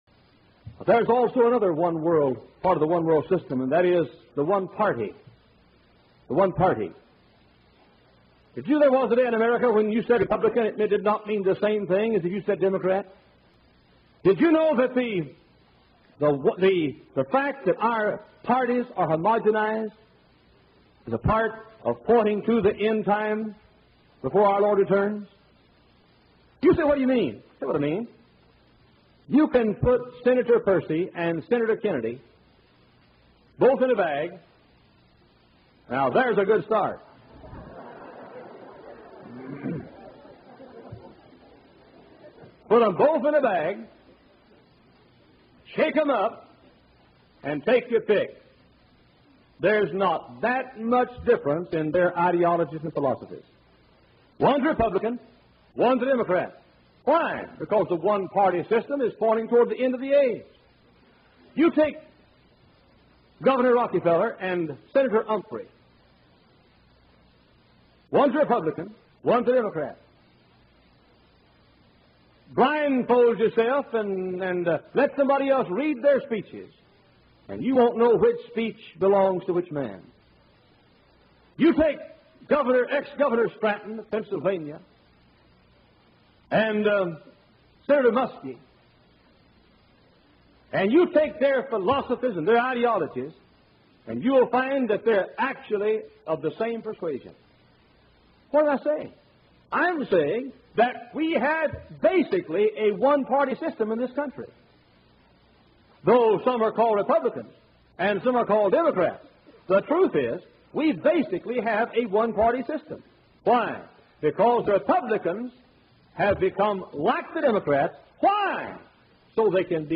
1970 Preacher Exposes Left Right Paradigm
1970_Preacher_Exposes_Left_Right_Paradigm.mp3